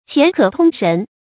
qián kě tōng shén
钱可通神发音